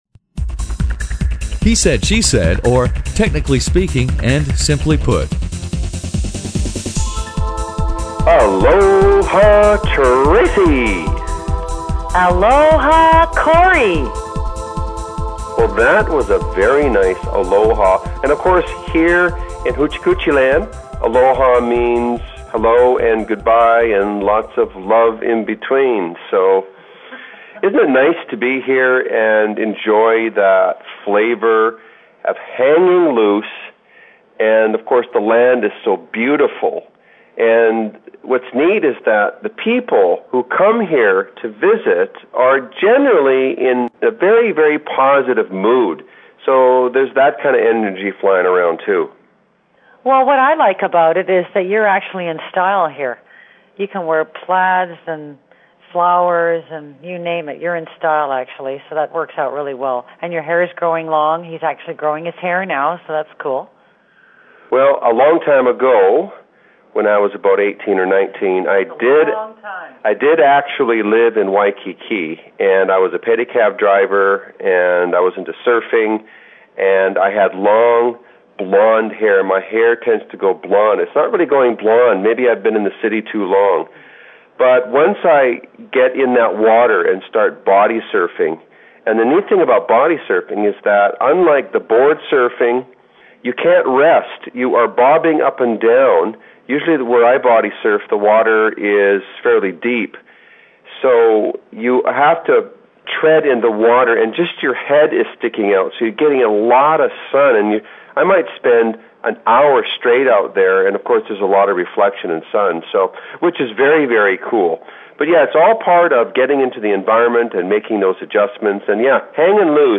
He-Said-She-Said Volume 9 Number 1 V9N1b - Topics What’s Cookin? Salads and Homemade Dressings Hot Topic: Life On The Road In Oahu Hawaii He-Said-She-Said is a spontaneous and humorous dialog